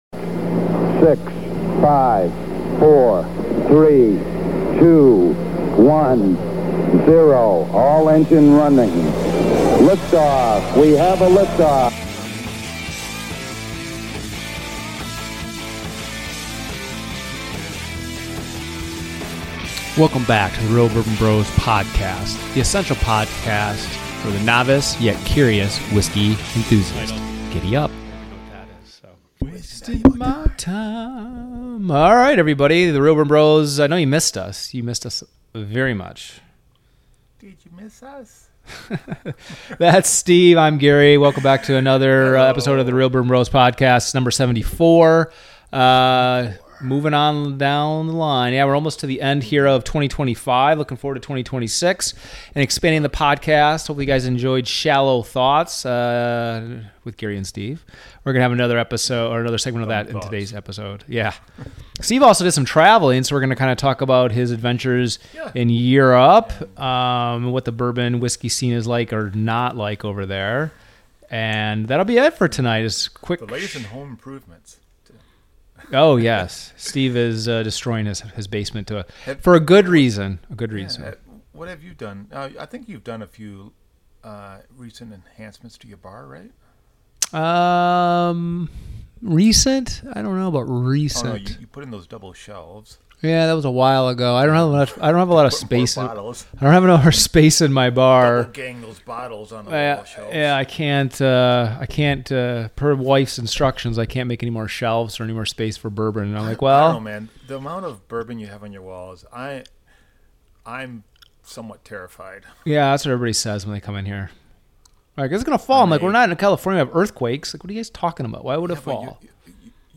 The essential podcast for the novice, yet curious whiskey enthusiast!We are two blood brothers chit chatting about one our common loves in life, whiskey!